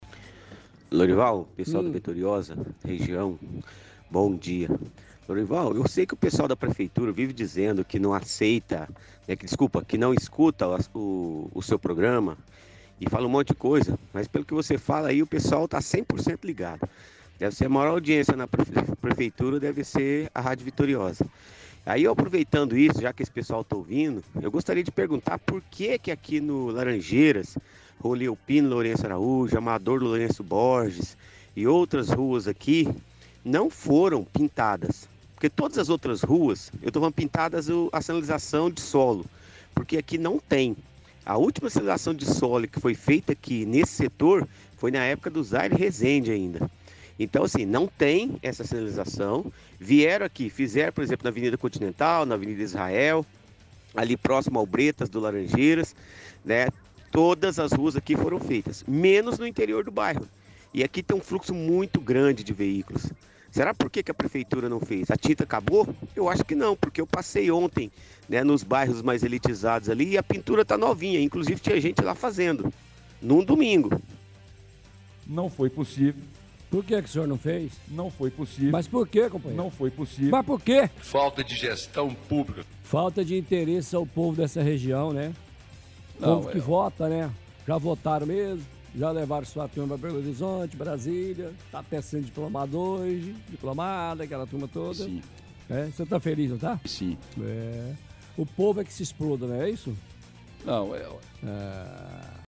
– Ouvinte reclama de falta de pintura em vias no bairro Laranjeiras, informando que a última pintura foi quando Zaire era prefeito.